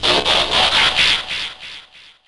SD _ SouthSide New Sound FX.wav